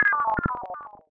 pyrogue/Oryx/sounds/interface/lose_a.wav at 6cd571476fb19fffe5376d3420ba79e842e89710
lose_a.wav